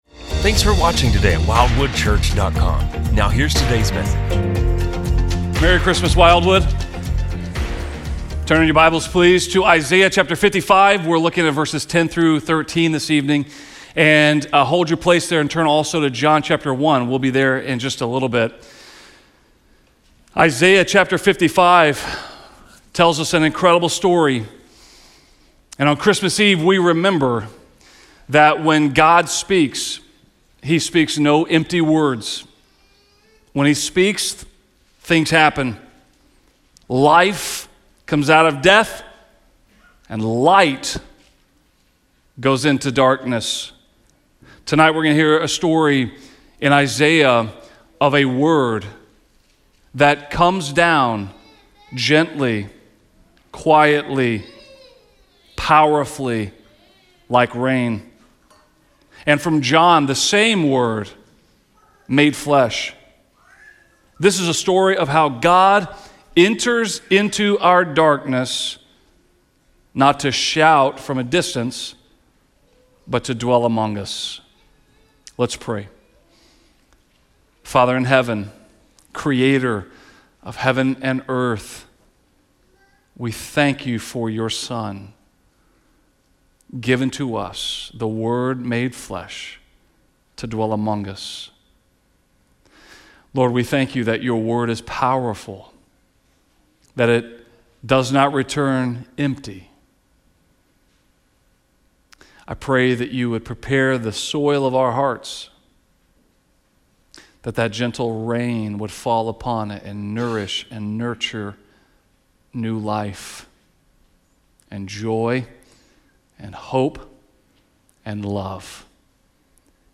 On Christmas Eve, we celebrate a God whose Word never returns empty. Drawing from Isaiah and John, this sermon traces how God’s Word comes like gentle rain—bringing life where there was death, light where there was darkness.